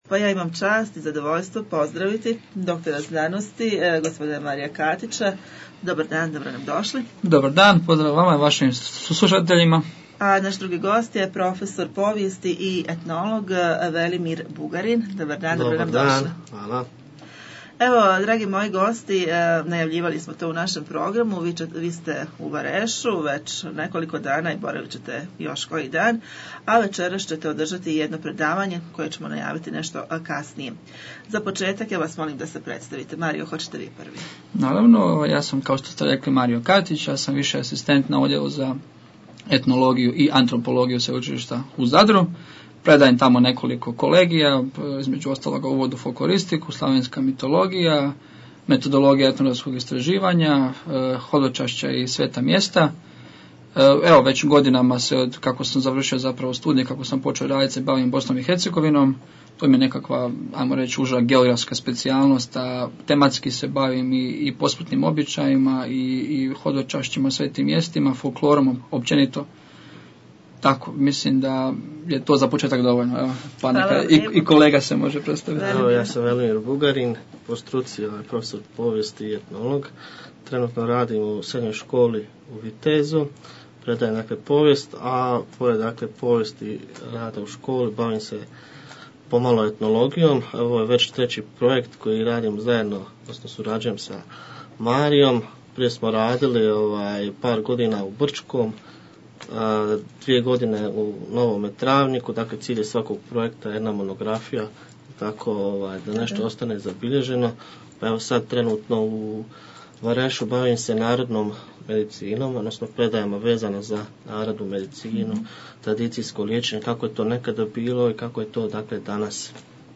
u našem studiju